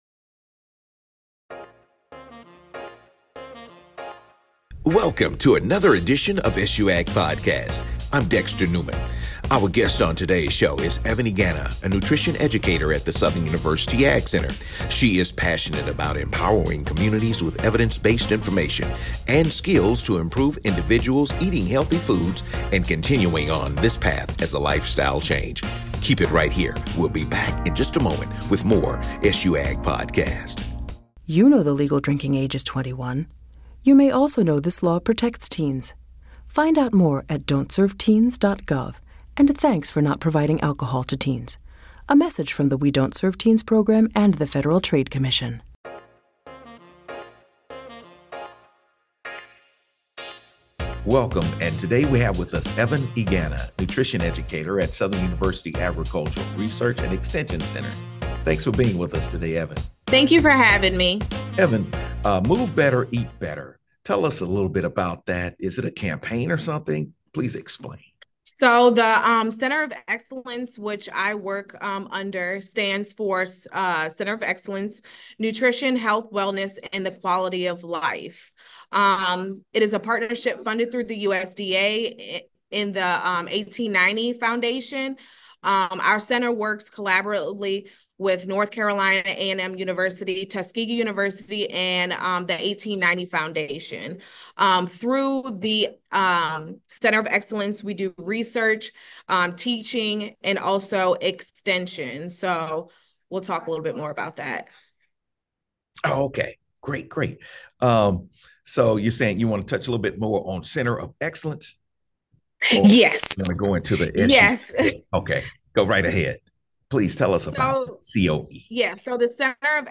Join us as we interview your jAg favorites, and bring you the latest in agriculture news.